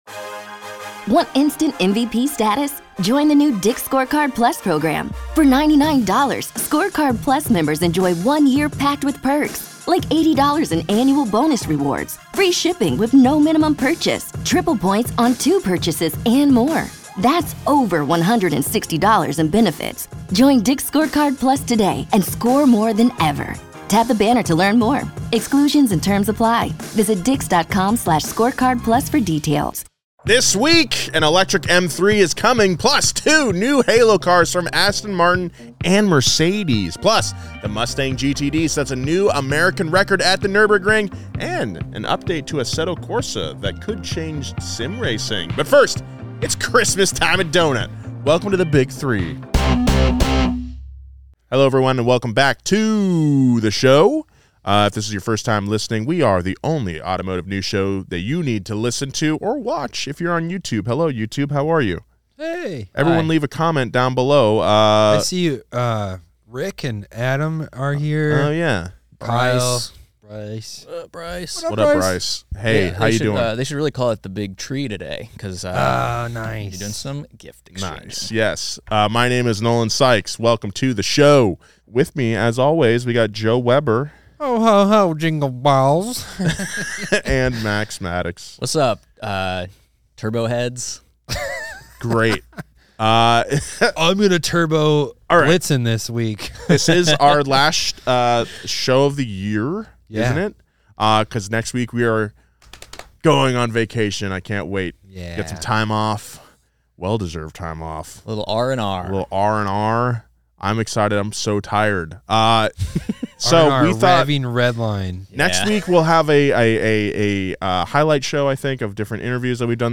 This week, the guys chat about Aston Martin and Mercedes AMG's newest halo cars, the new EV M3 and a Renault blast from the past. Plus, which reindeer names would make the best cars?